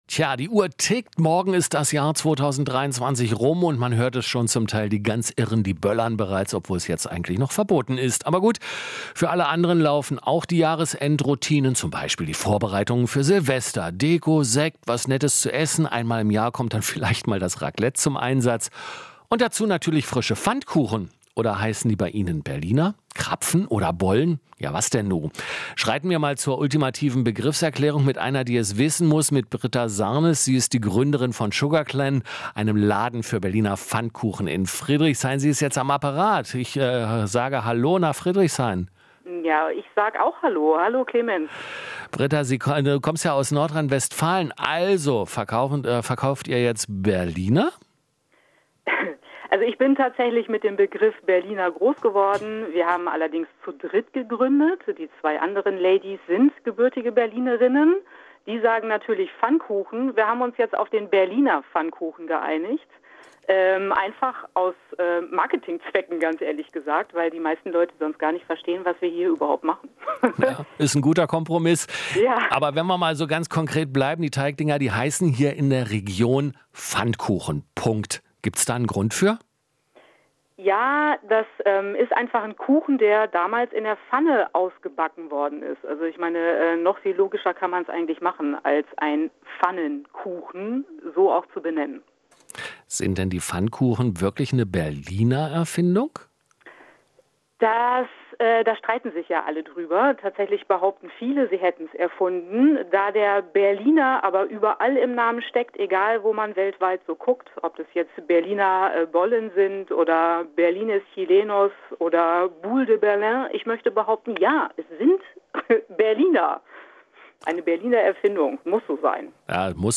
Interview - Silvester-Gebäck: Heißt es jetzt Pfannkuchen oder Berliner?